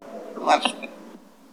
her own ... click "play" to hear her words.
Arielle understands speech and speaks thoughtfully using English